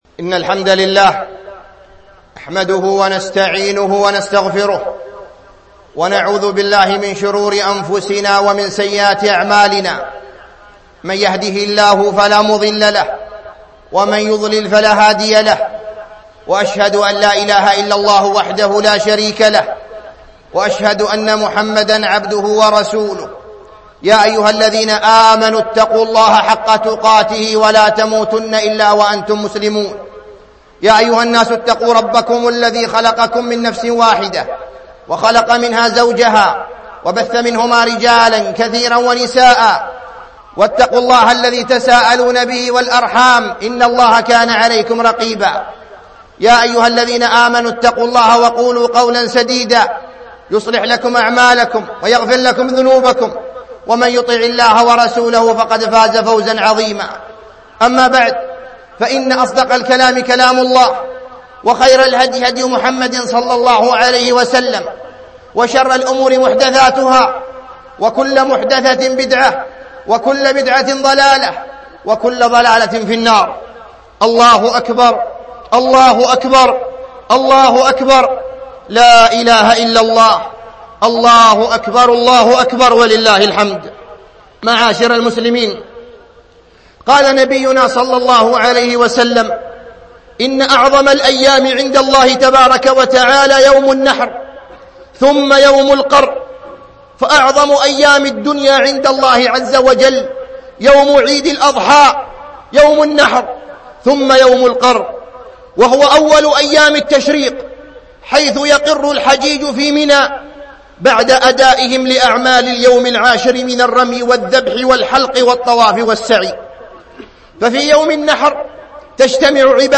خطبة عيد الأضحى 1436هـ